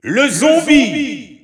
The announcer saying Zombie's name as "le zombie" in French.
Zombie_French_Alt_Announcer_SSBU.wav